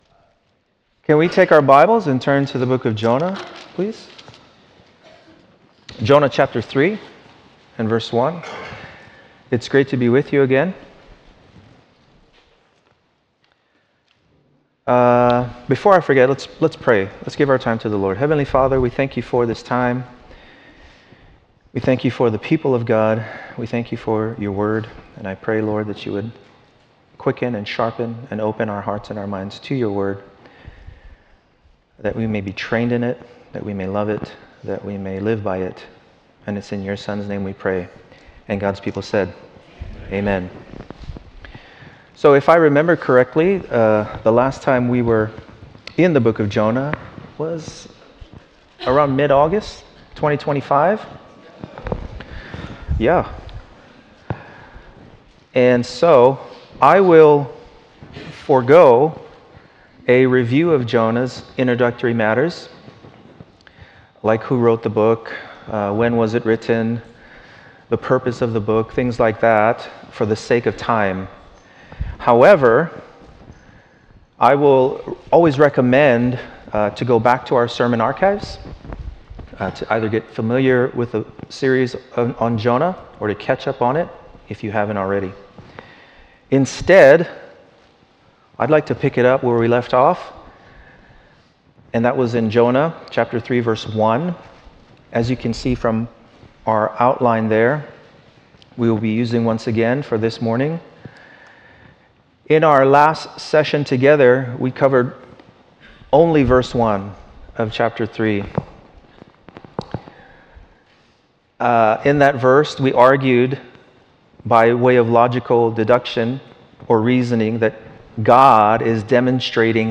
Home / Sermons / Jonah 012 – Proclaim What?